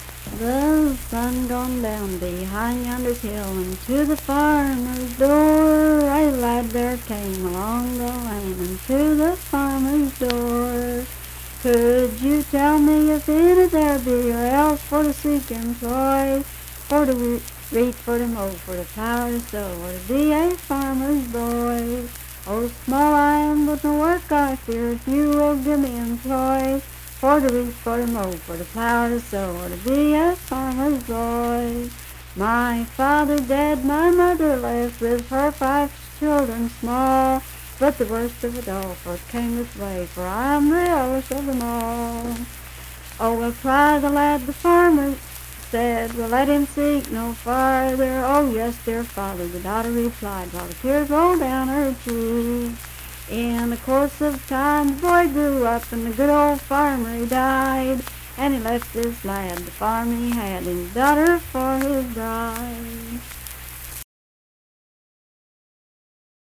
Verse-refrain 6(4w/R).
Voice (sung)
Sutton (W. Va.), Braxton County (W. Va.)